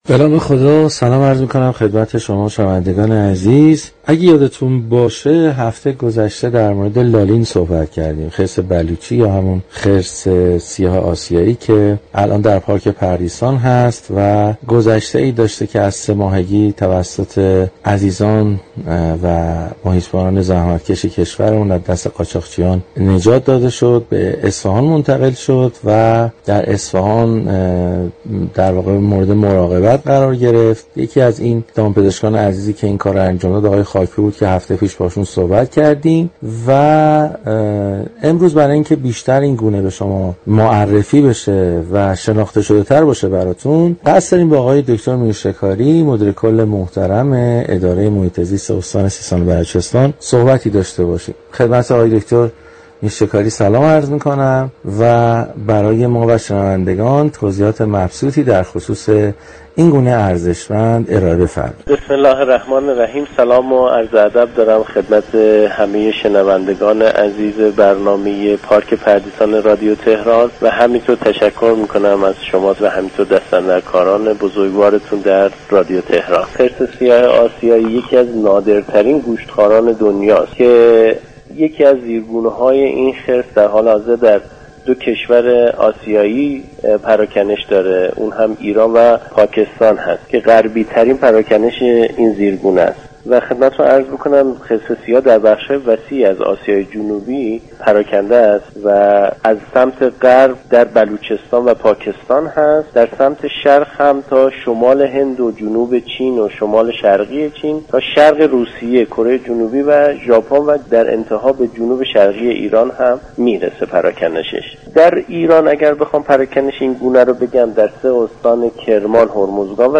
به گزارش پایگاه اطلاع رسانی رادیو تهران، داود میرشكاری مدیركل اداره محیط زیست استان سیستان و بلوچستان در گفت و گو با «پارك پردیسان» درخصوص خرس سیاه آسیایی اظهار داشت: این خرس یكی از نادرترین گوشتخواران دنیا است.